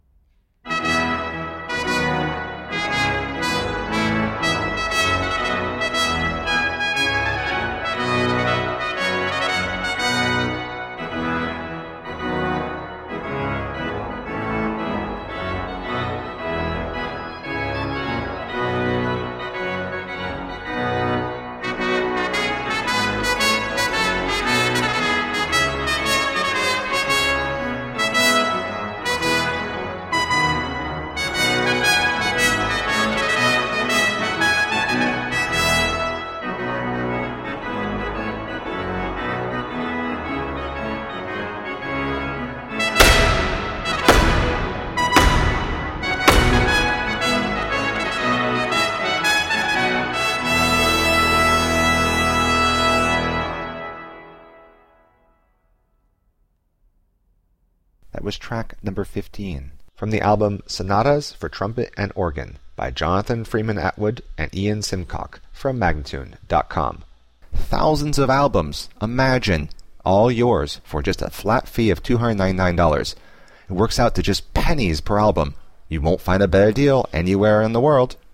Brilliant baroque masterpieces in virtuoso style.
Classical, Chamber Music, Baroque, Instrumental